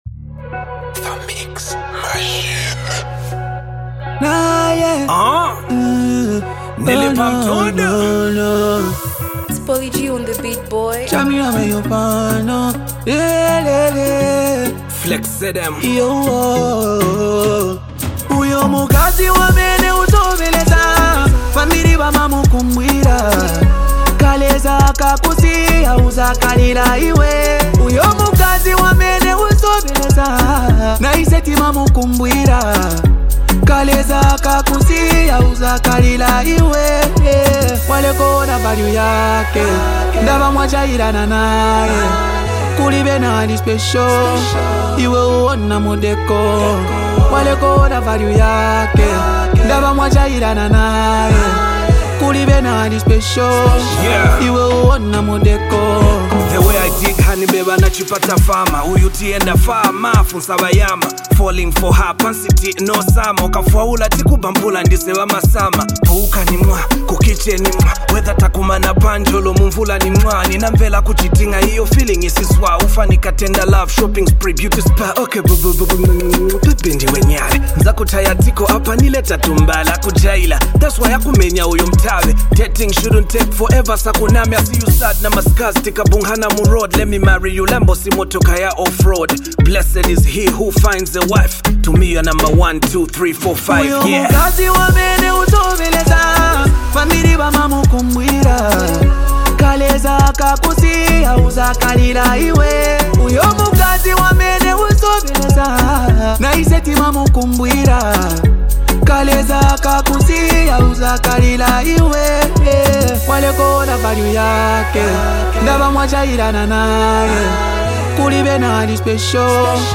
Zambian hip hop